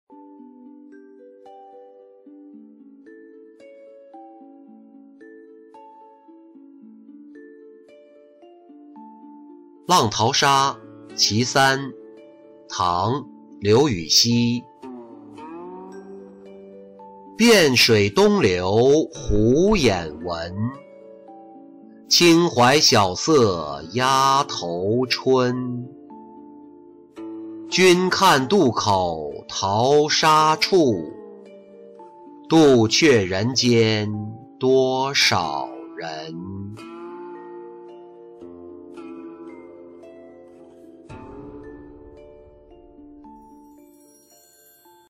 浪淘沙·其三-音频朗读